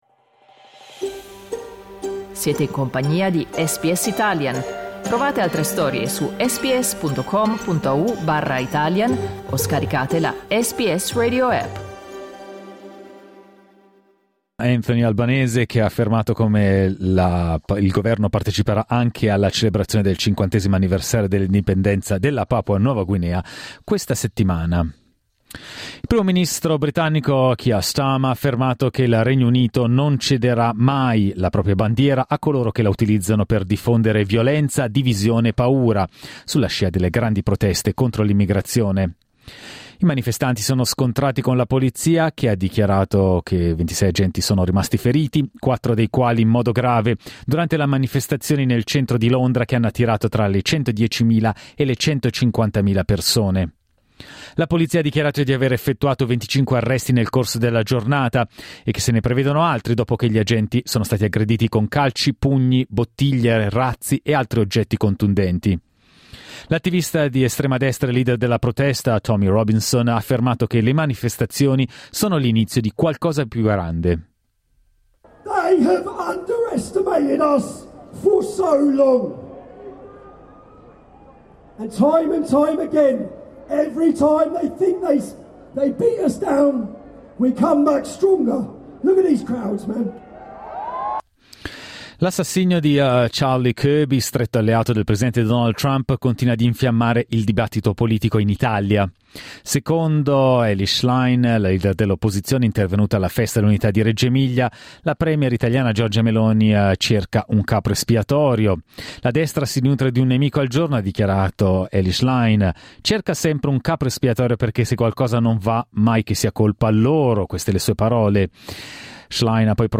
Giornale radio lunedì 15 settembre 2025
Il notiziario di SBS in italiano.